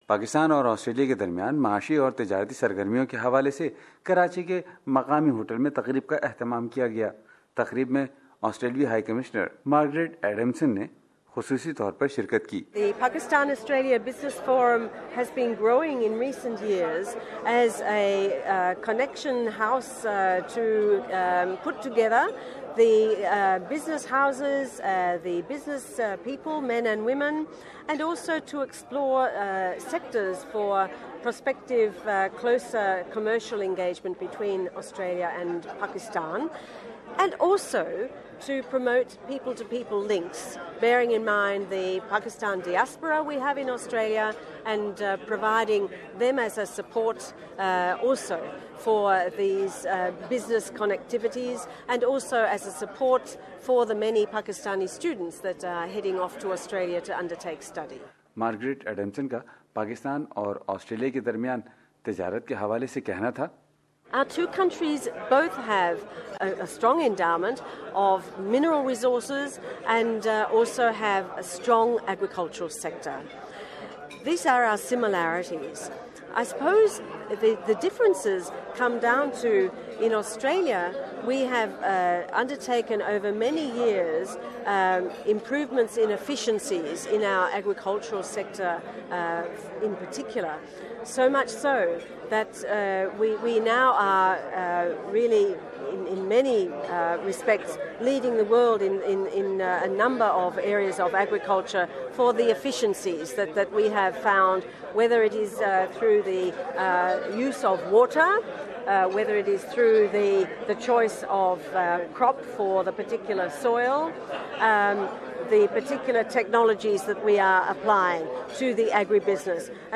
Australian High Commissioner Margaret Adamson says her country wants to see trade that is more evenly balanced while speaking to SBS Radio Urdu during a forum held by Pakistan-Australia Business Forum (PABF) and chamber of commerce Karachi. Pakistan, she said, had a vast pool of resources and much of that resource lay in the talent of the business community, she said.